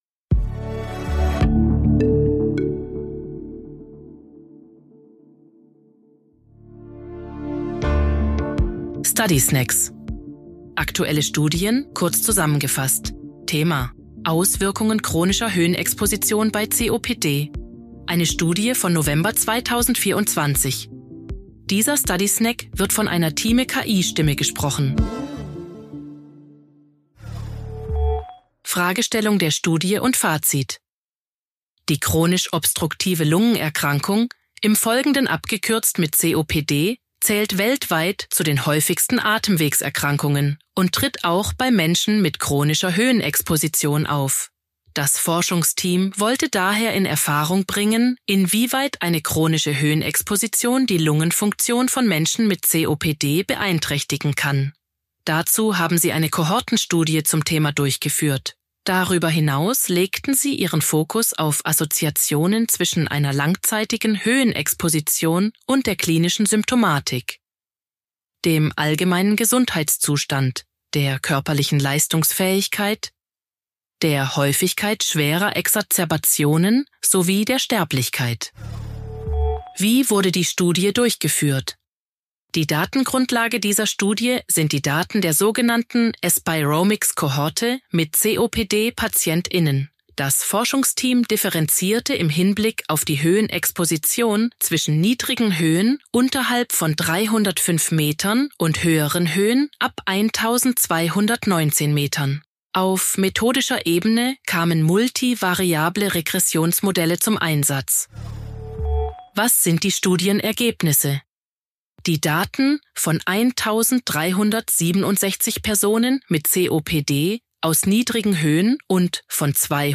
sind mit Hilfe von künstlicher Intelligenz (KI) oder maschineller
Übersetzungstechnologie gesprochene Texte enthalten